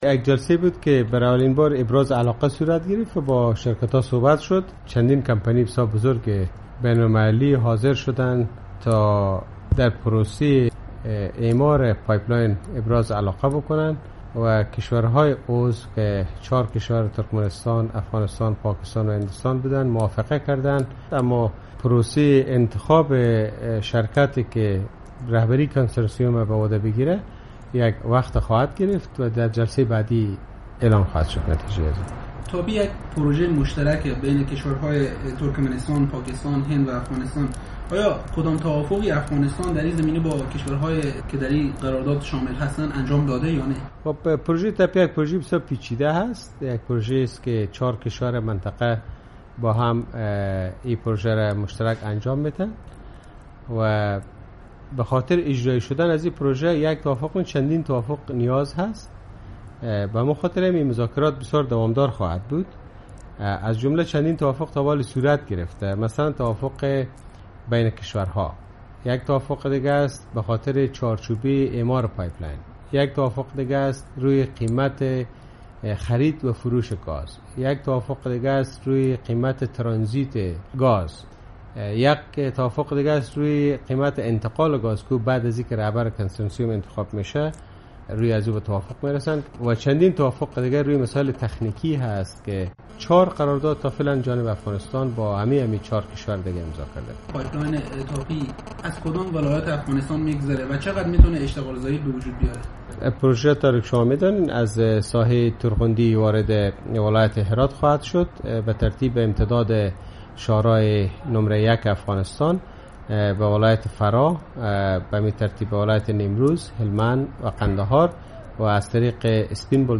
مصاحبه ها